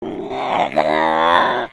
sounds / monsters / cat / c2_die_1.ogg
c2_die_1.ogg